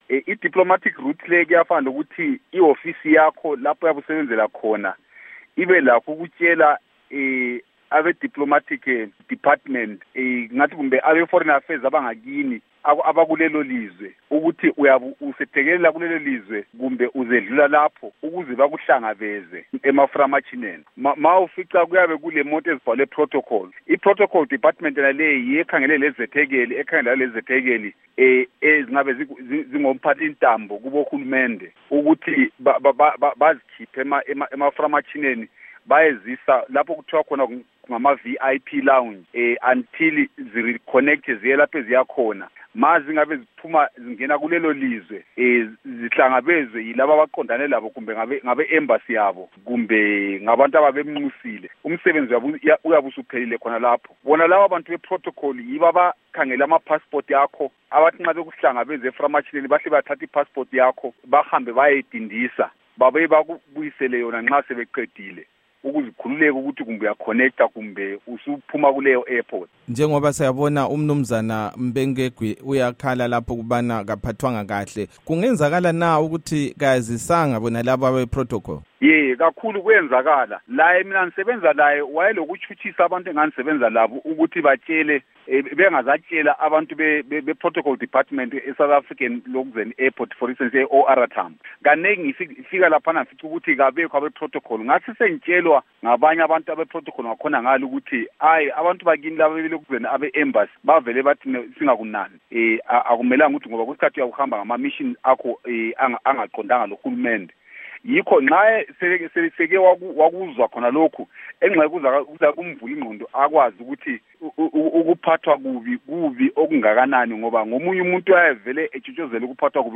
Ingxoxo loMnu. Moses Mzila Ndlovu